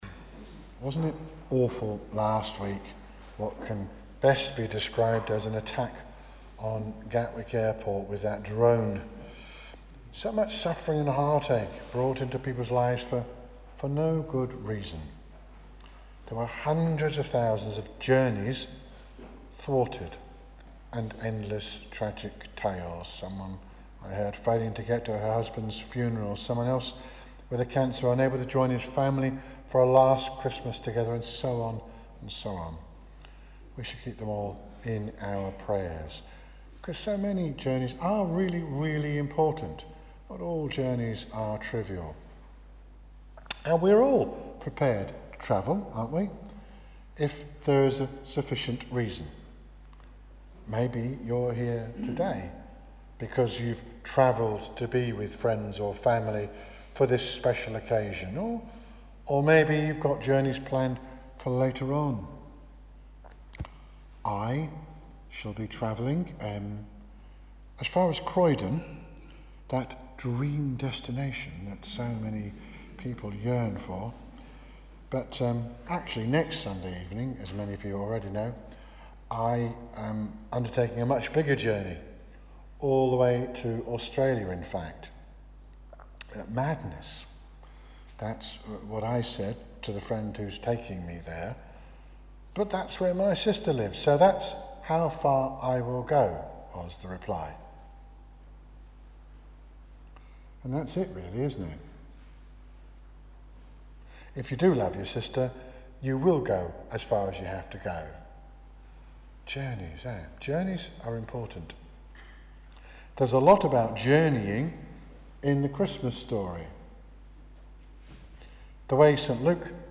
Sermons Archive